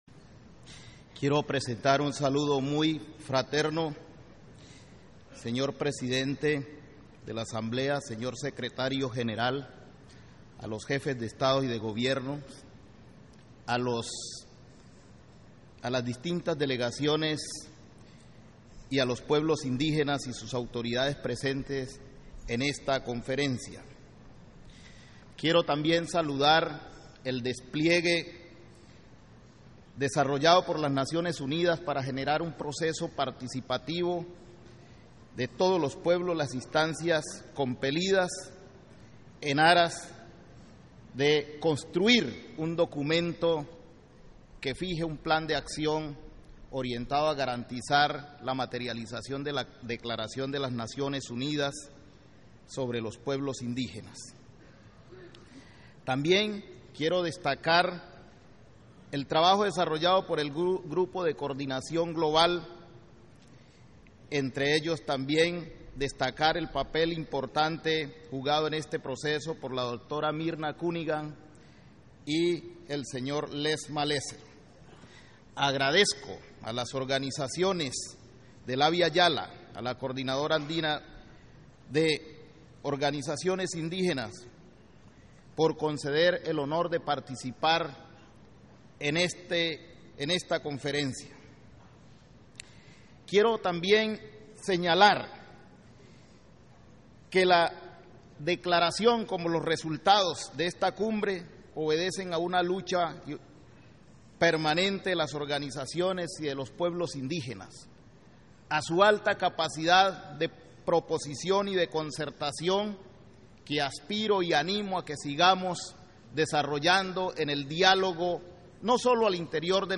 Audio del discurso de Luis Evelis, Senador de la República de Colombia en su calidad de Representante de la Región Indígena de América del Sur, Central y del Caribe, en la Conferencia Mundial Sobre Pueblos Indígenas - NODAL
Audio del discurso de Luis Evelis, Senador de la República de Colombia en su calidad de Representante de la Región Indígena de América del Sur, Central y del Caribe, en la Conferencia Mundial Sobre Pueblos Indígenas